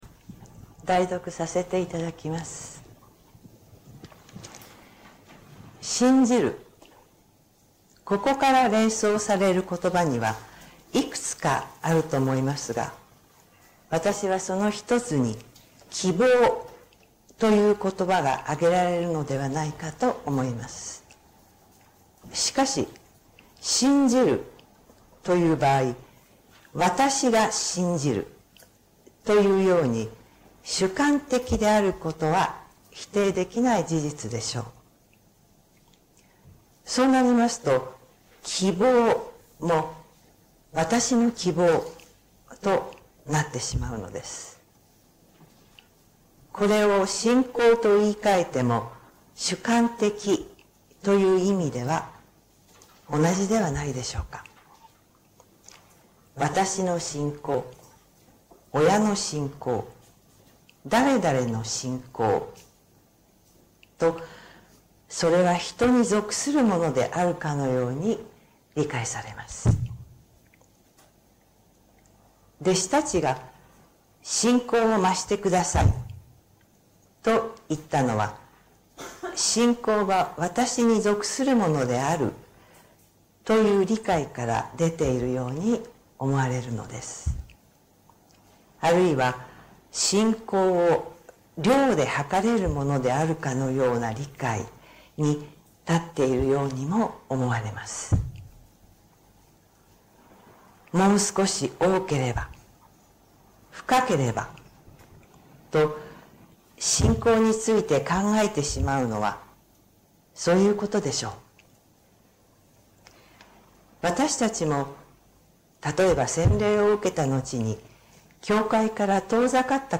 説教音声 最近の投稿 2026年3月22日 礼拝・四旬節第5主日 3月22日 「死んでも生きる？」